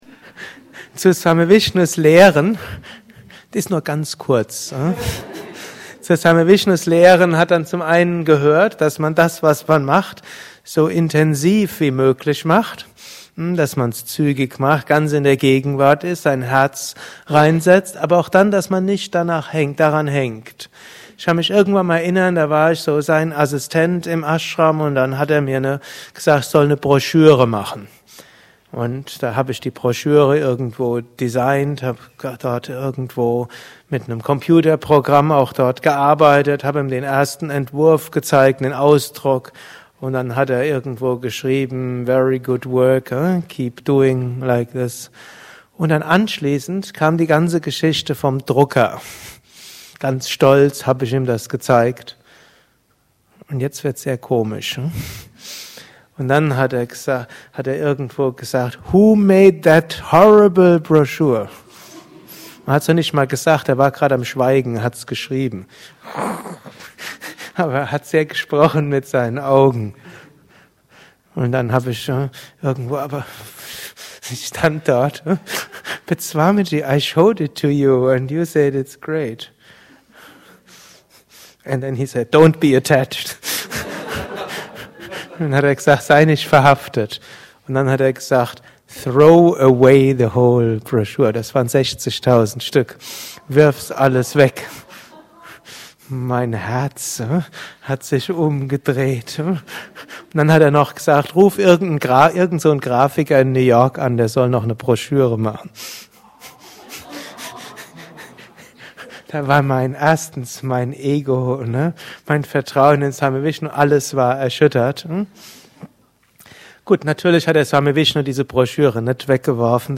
Lesung
Gelesen im Anschluss nach einer Meditation im Haus Yoga Vidya Bad Meinberg.